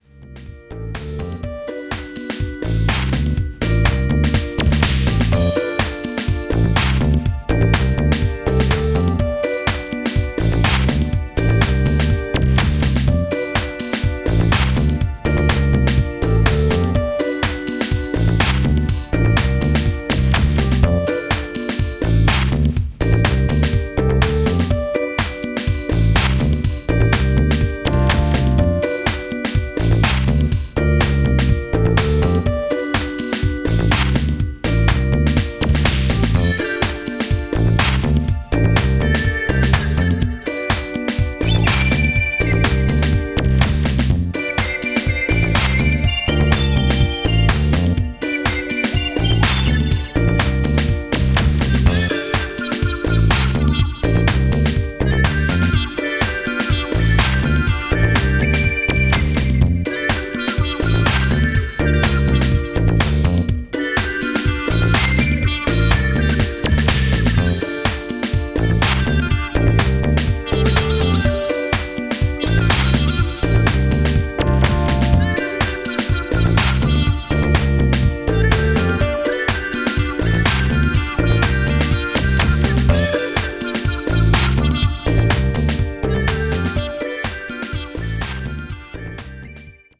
歯切れ良いプロト・ハウスのグルーヴにクリアなメロディーが人懐こく溶ける